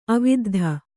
♪ aviddha